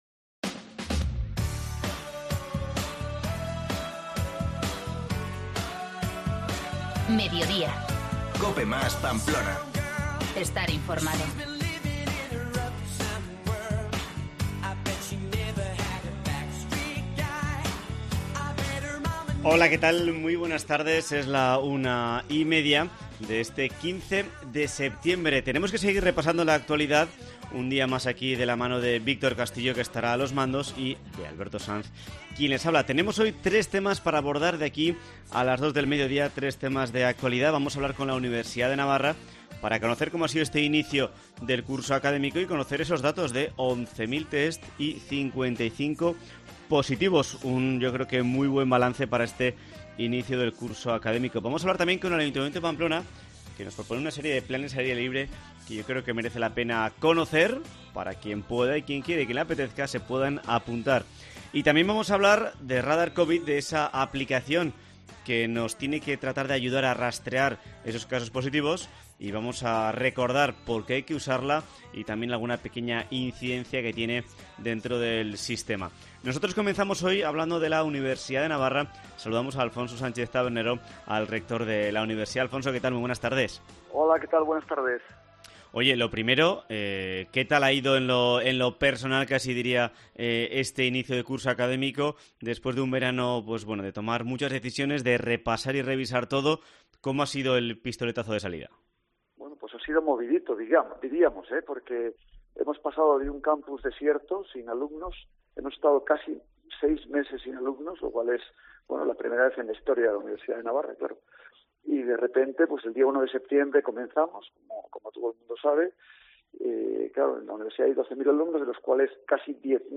Entrevista en COPE Navarra